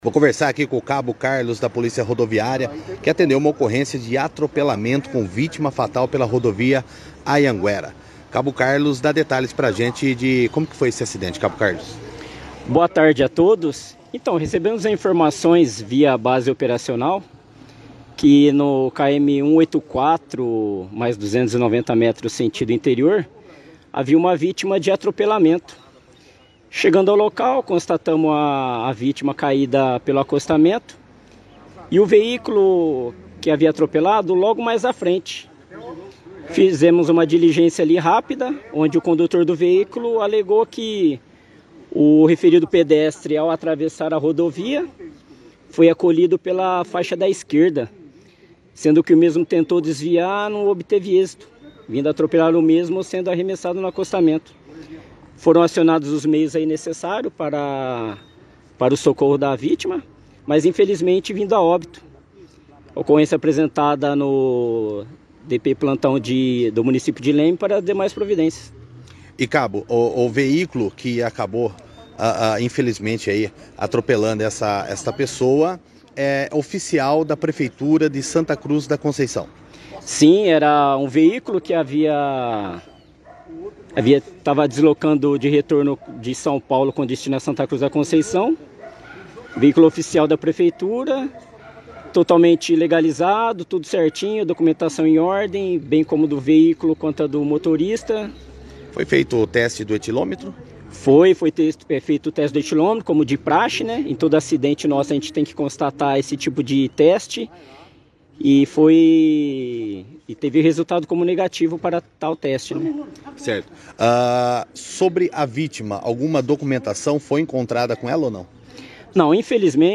Após o atropelamento, o resgate da concessionária foi acionado, fez os trabalhos de socorro da vítima, mas ela não resistiu e veio a óbito. Mais informações com o repórter